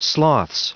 Prononciation du mot sloths en anglais (fichier audio)
sloths.wav